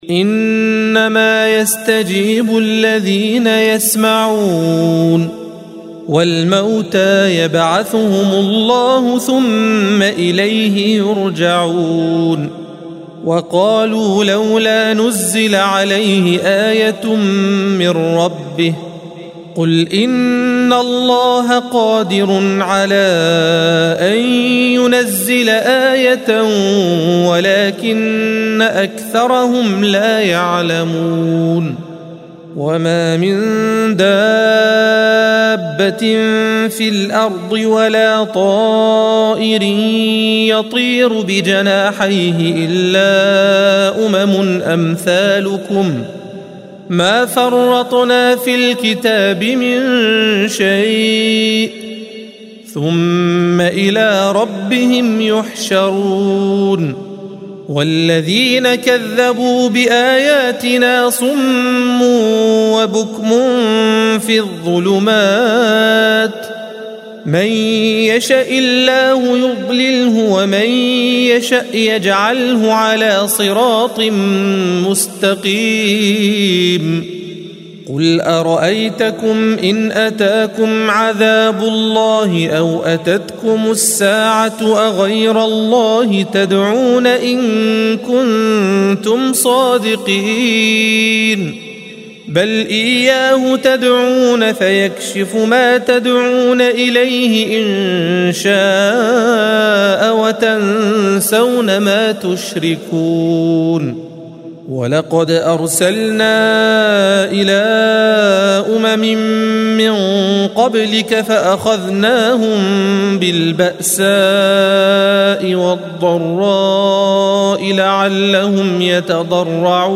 الصفحة 132 - القارئ